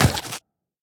biter-roar-mid-7.ogg